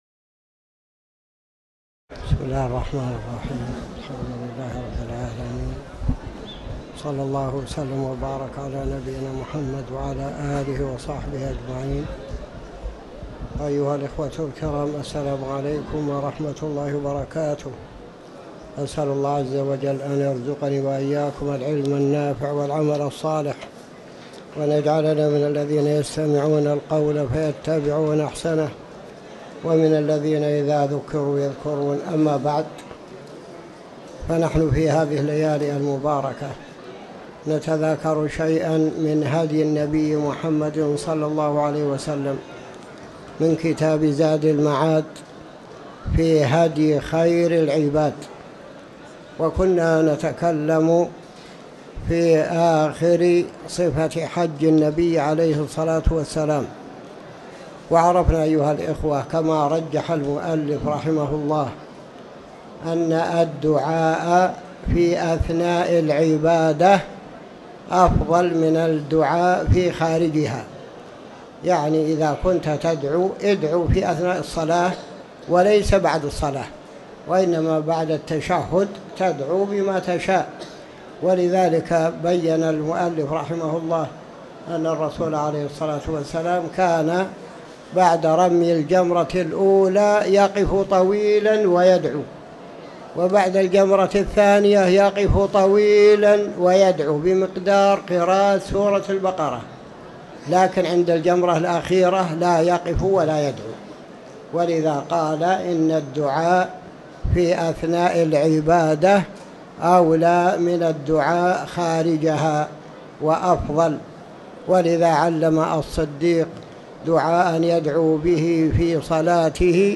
تاريخ النشر ١٥ جمادى الأولى ١٤٤٠ هـ المكان: المسجد الحرام الشيخ